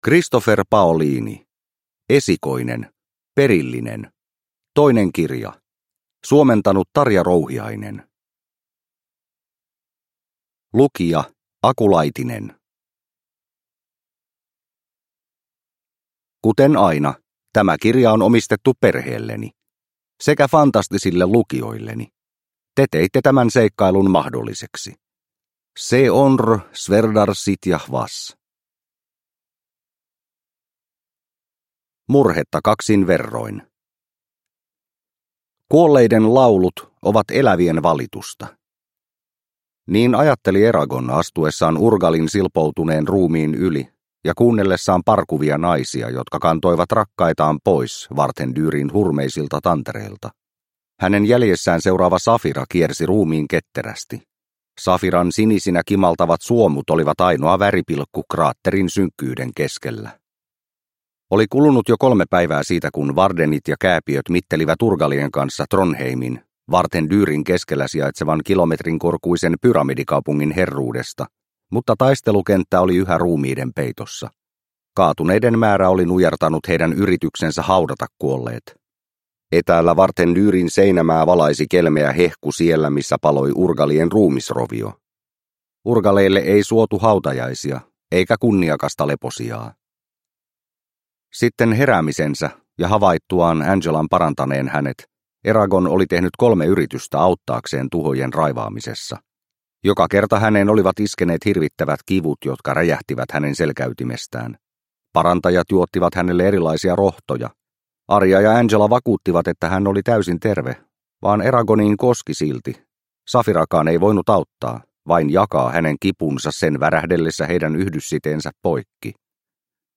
Esikoinen – Ljudbok – Laddas ner